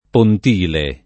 pontile [ pont & le ] s. m.